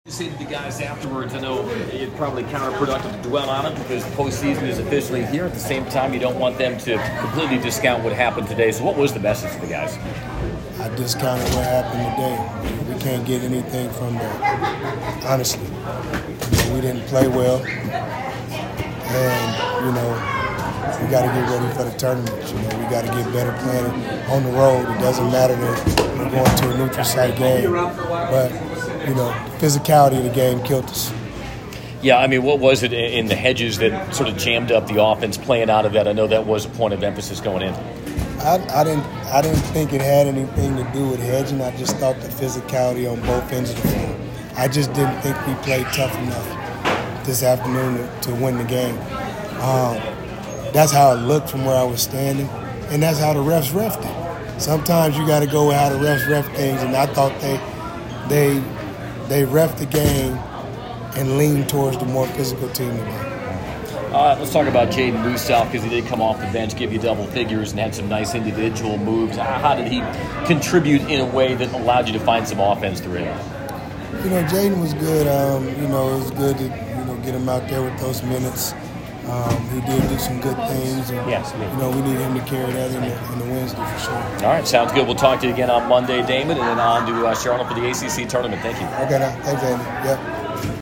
POST-GAME AUDIO
Head coach Damon Stoudamire